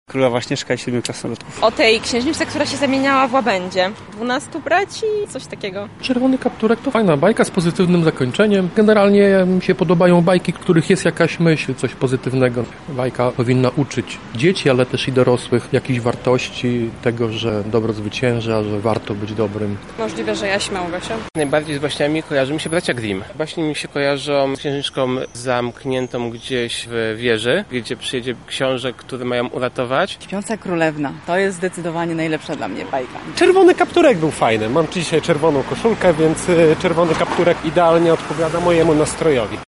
[SONDA] Jakie są ulubione baśnie lublinian?
Z racji tego święta zapytaliśmy lublinian o to, jaka jest ich ulubiona baśń.